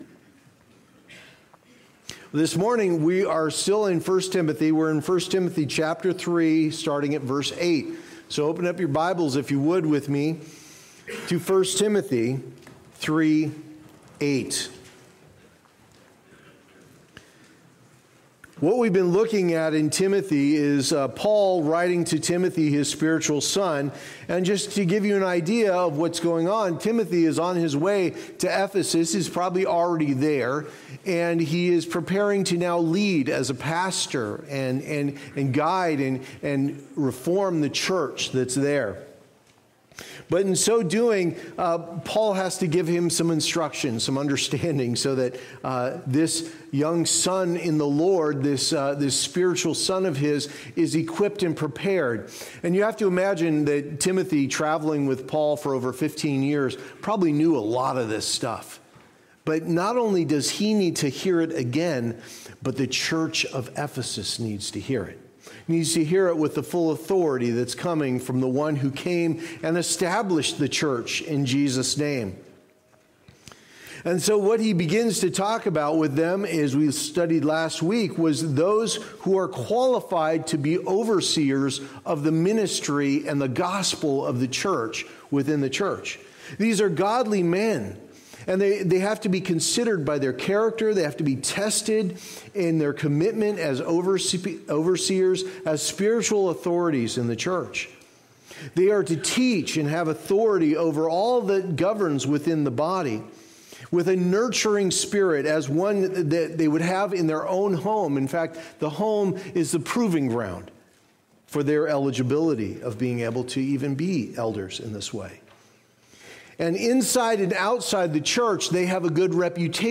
Services: Sunday Morning Service